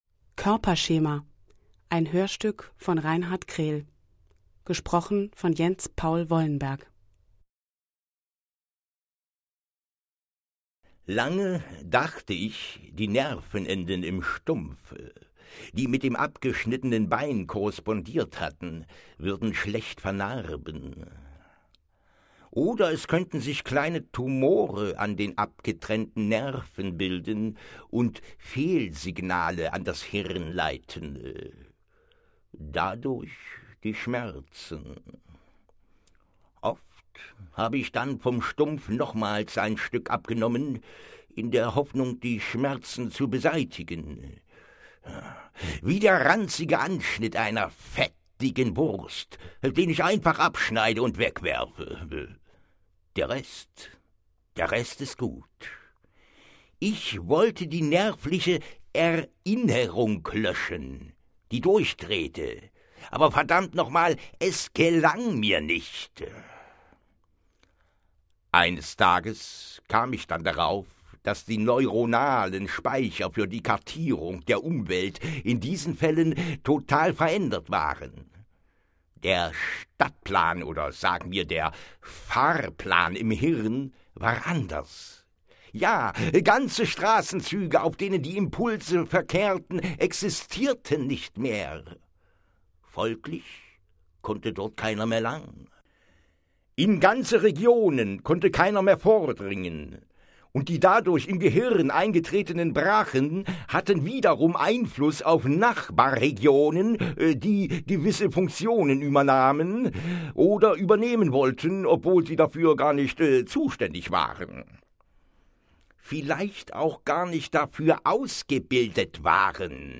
H�rst�ck